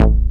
bseTTE52023hardcore-A.wav